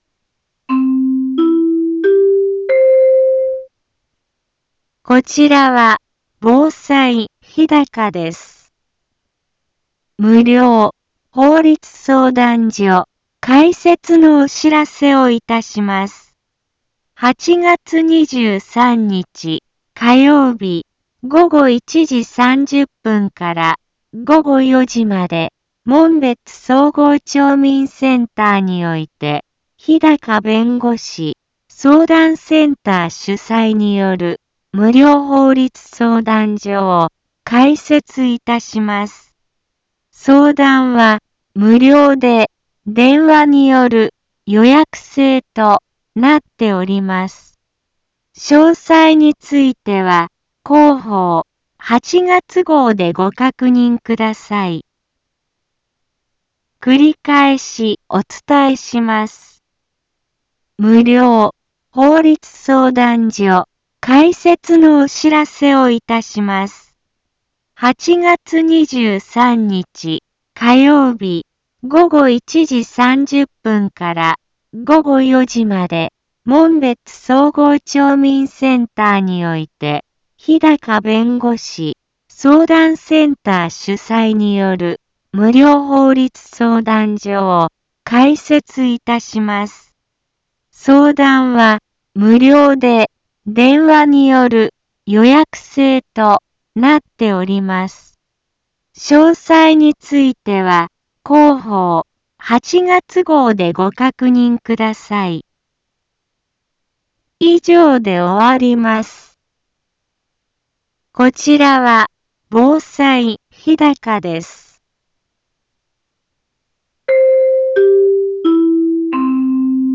一般放送情報
Back Home 一般放送情報 音声放送 再生 一般放送情報 登録日時：2022-08-16 10:04:13 タイトル：無料法律相談会のお知らせ インフォメーション：こちらは防災日高です。